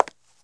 CONCRETE R1.WAV